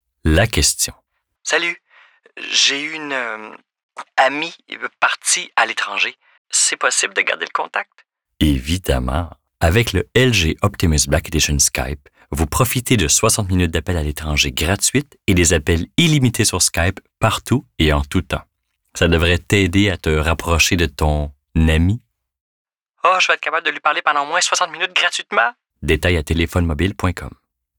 Démo de voix
Publicité Téléphonie - Démo Fictif